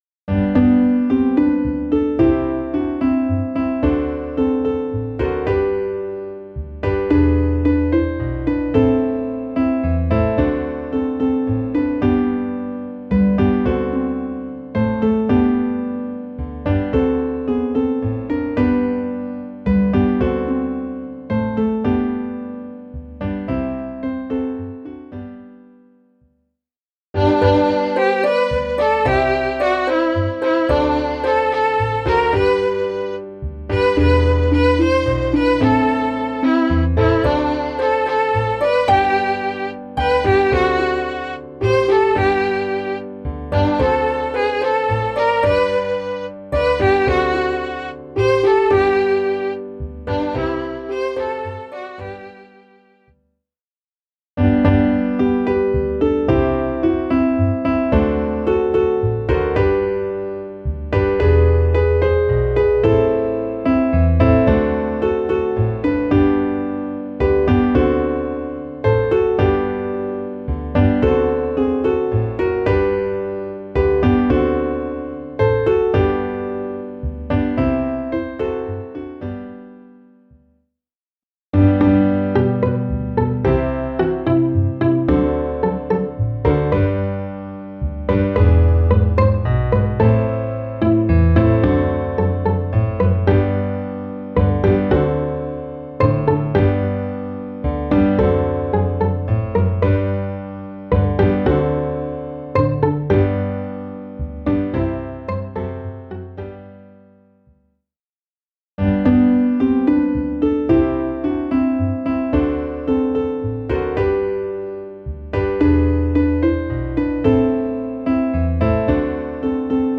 Klaviersatz 6/8